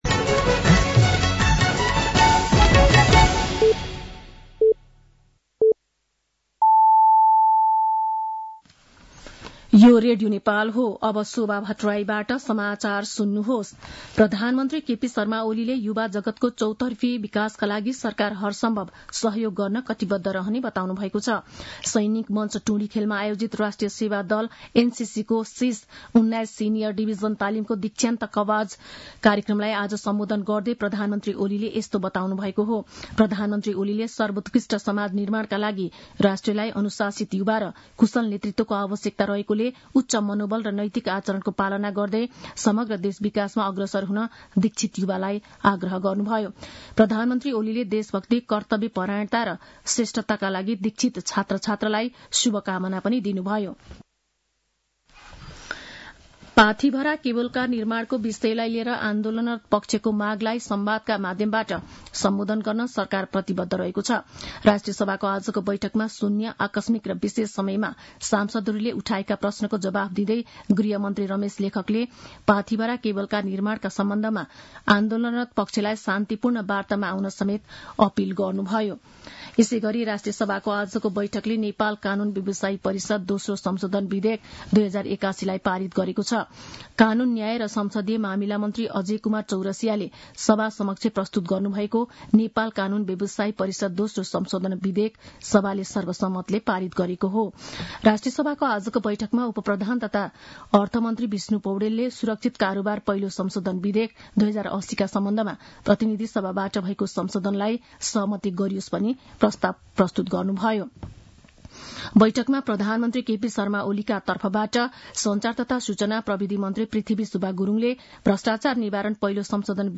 साँझ ५ बजेको नेपाली समाचार : १९ फागुन , २०८१
5-pm-news.mp3